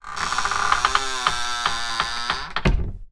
Медленное закрытие крышки гроба